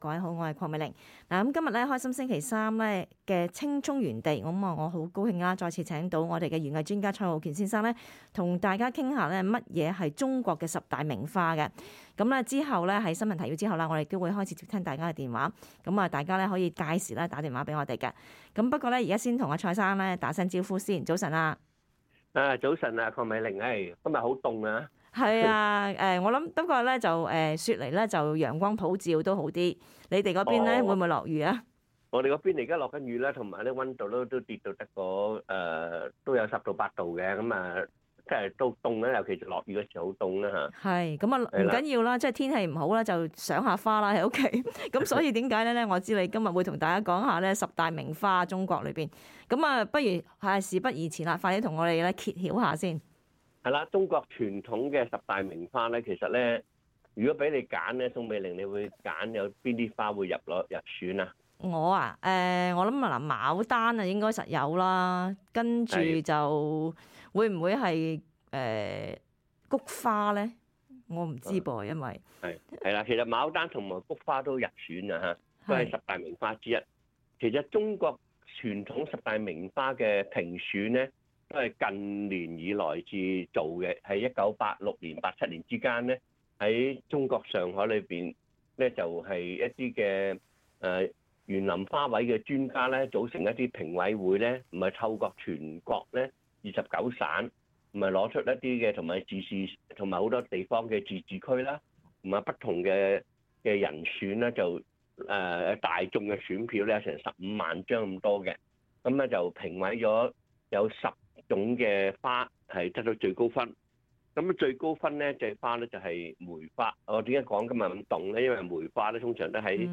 另外，他亦会解答听众来电，包括如何种植海棠花、姜和火龙果等。